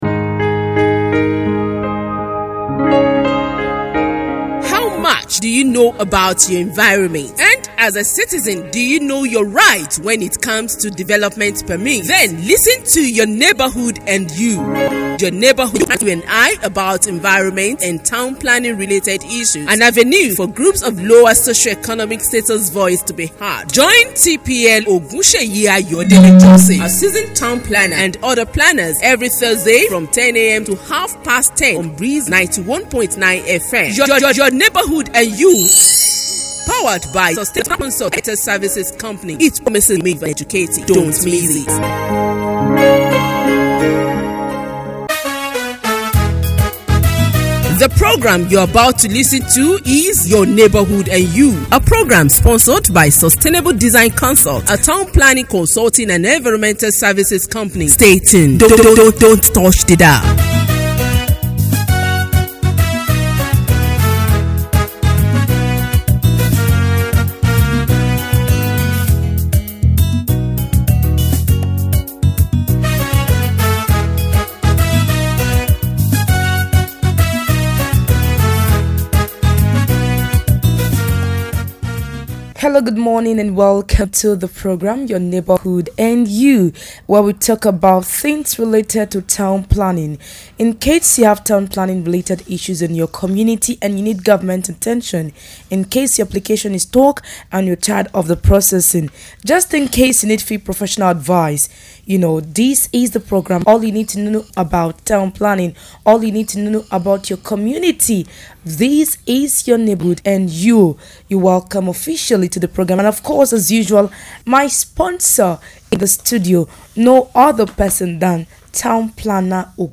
A live Radio program on Breeze 91.9FM